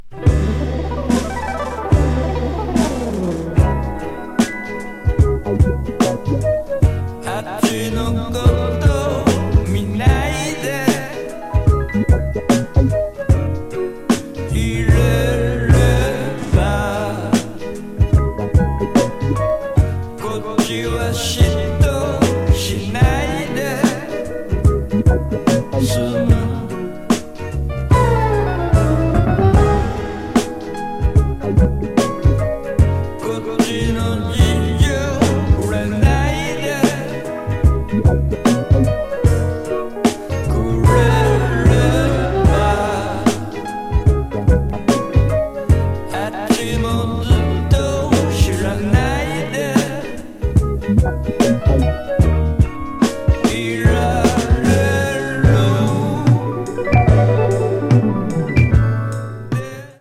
New Release Soul / Funk